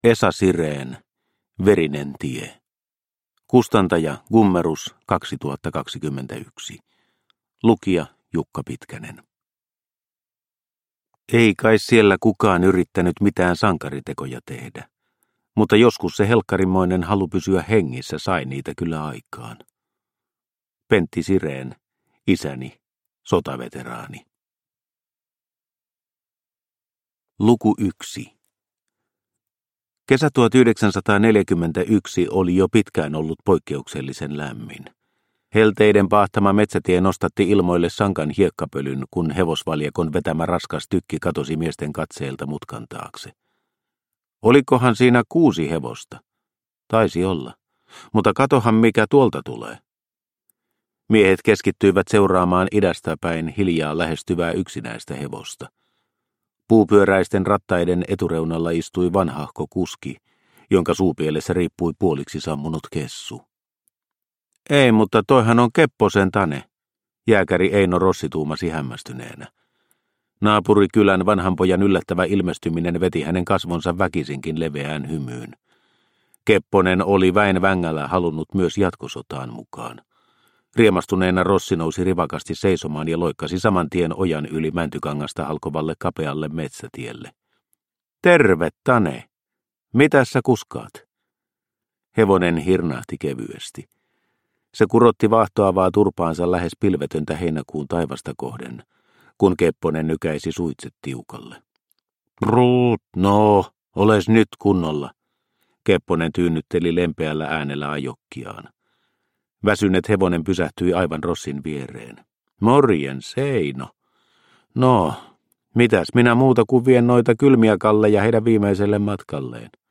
Verinen tie – Ljudbok – Laddas ner